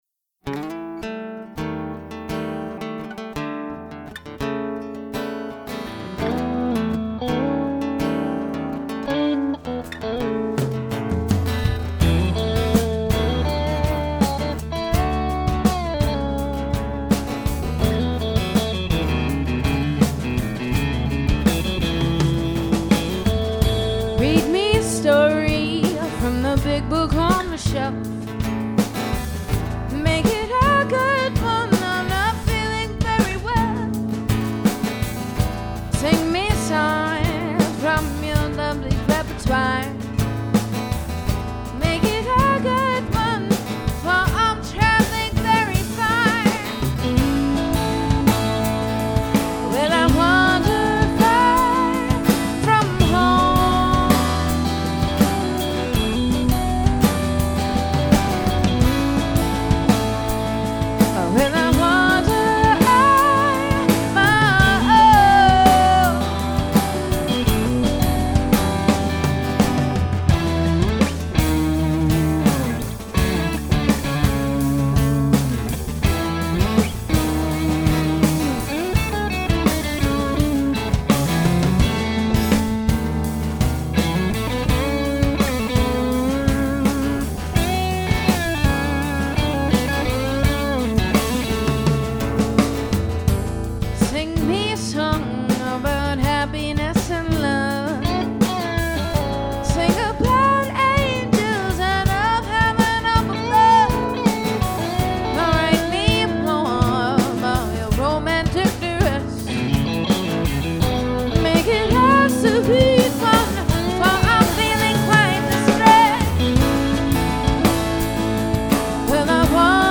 latin undercurrent
it's a rough mix of the CD's title track